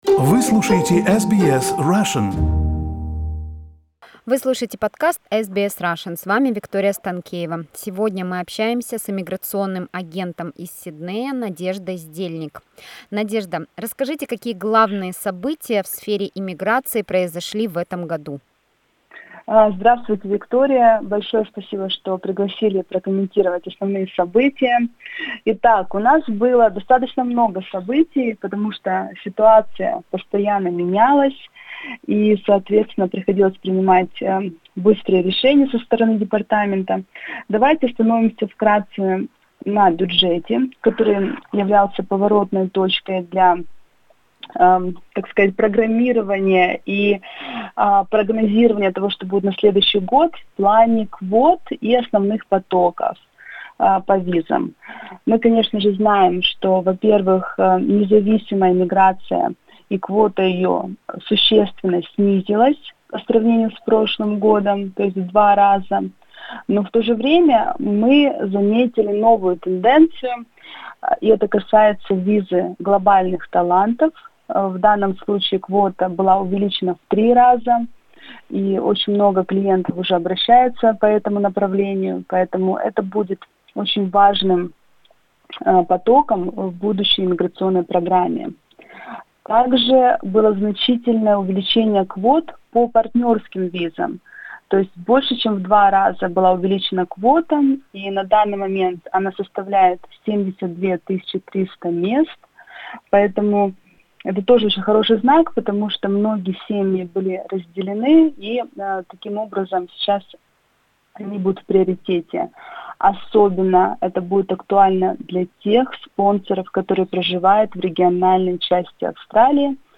Из интервью вы узнаете о том: какие новые подклассы бизнес-виз появились в 2020 году; как увеличились квоты по партнерским визам; какие изменения произошли в сфере студенческих виз; какие кадровые изменения произошли в министерстве иммиграции; и о других новостях.